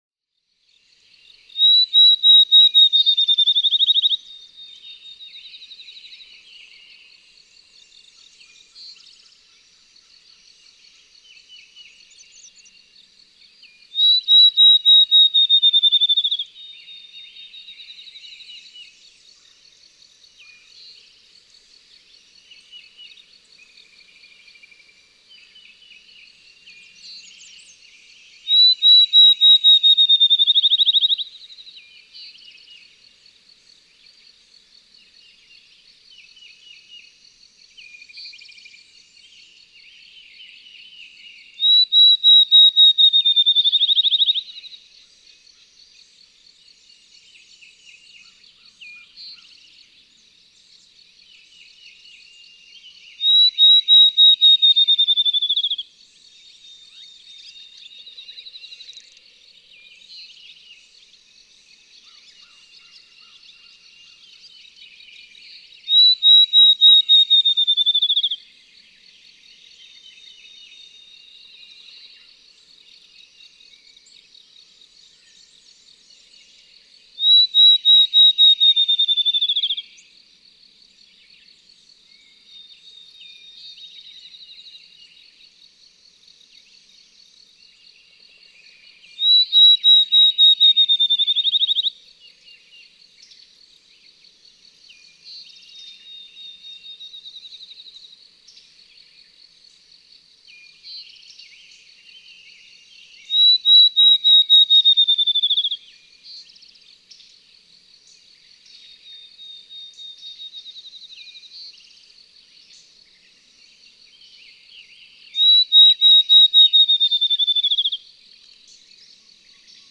Accelerando—field sparrow
The overall effect is that of a "bouncing ball," teeew teeew teew teew tewtewtewteteteteetitititititititi, accelerating to the end.
Land Between the Lakes, Kentucky.
643_Field_Sparrow.mp3